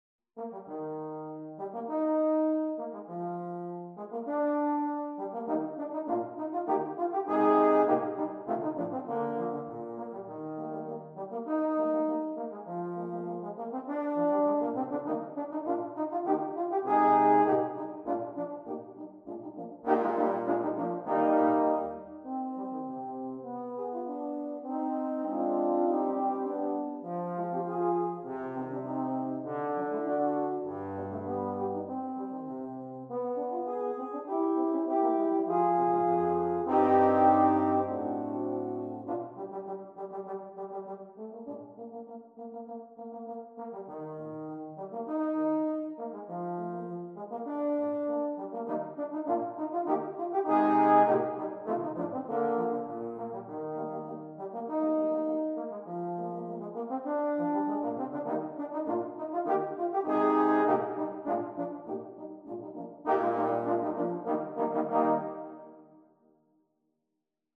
Voicing: Euphonium Quartet